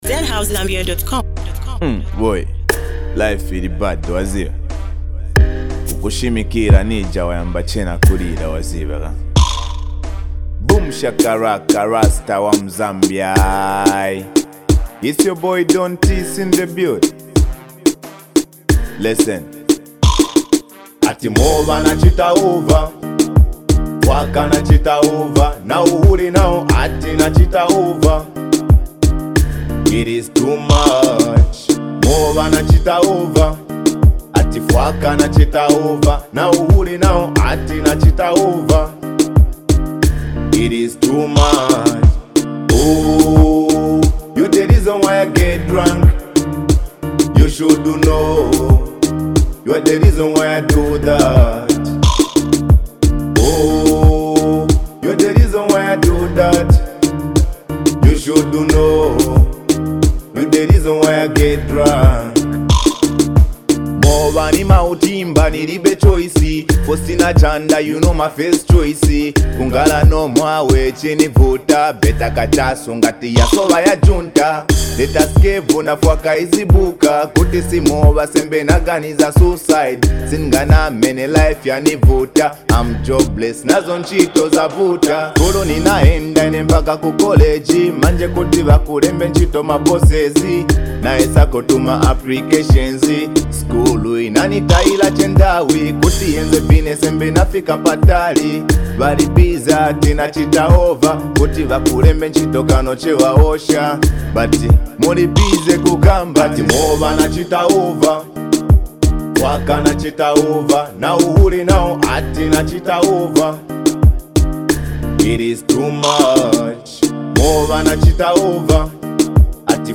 a powerful jam that blends raw emotion with catchy vibes.
this track is both soulful and energetic